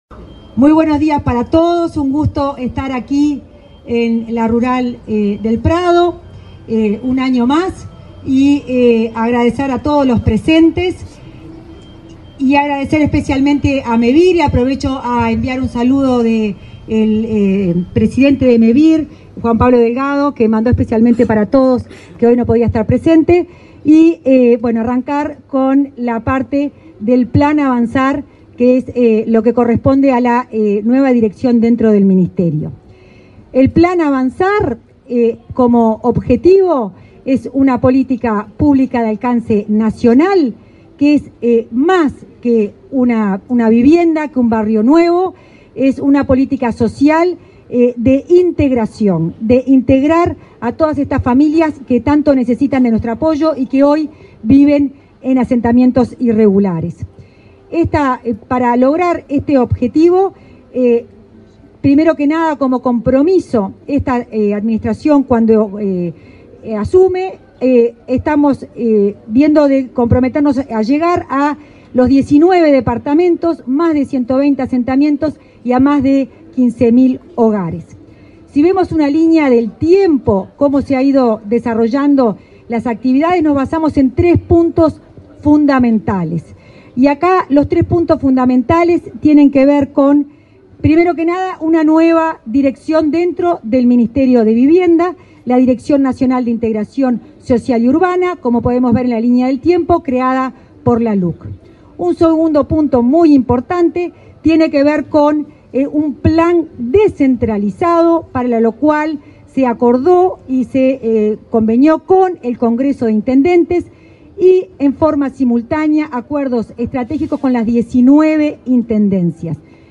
Palabras de autoridades del Ministerio de Vivienda
Palabras de autoridades del Ministerio de Vivienda 13/09/2024 Compartir Facebook X Copiar enlace WhatsApp LinkedIn La directora de Integración Social y Urbana del Ministerio de Vivienda, Florencia Arbeleche; el subsecretario de la cartera, Tabaré Hackenbruch, y el ministro Raúl Lozano expusieron acerca de la vivienda social en Uruguay, este viernes 13 en el stand de Mevir, en la Expo Prado 2024.